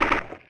New UI SFX
ui_bonk_v1.wav